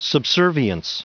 Prononciation du mot subservience en anglais (fichier audio)
Prononciation du mot : subservience